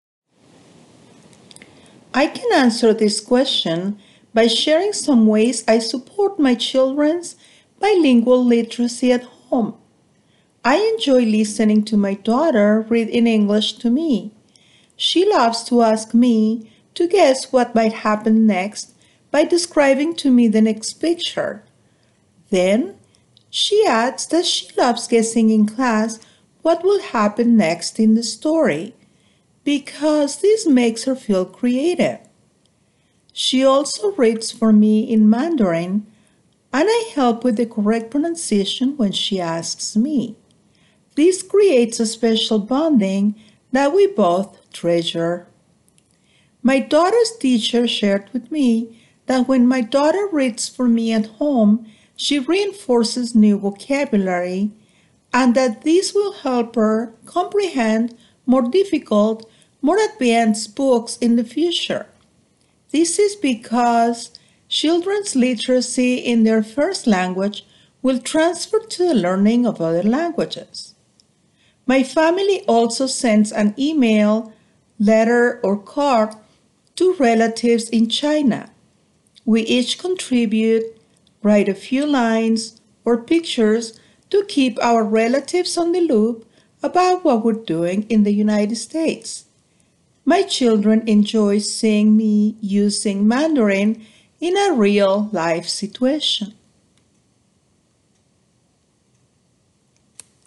[Note: In the transcript below, ellipses indicate that the speaker paused.]
The response effectively communicates clear and logically sequenced ideas delivered with a consistent flow of speech, few pauses, intelligible pronunciation, and appropriate intonation.
Examples of such errors include some inconsistency in the flow of speech and few pauses.